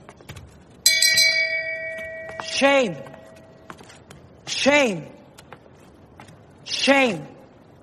Shame Bell Sound Button - Free Download & Play
Reactions Soundboard793 views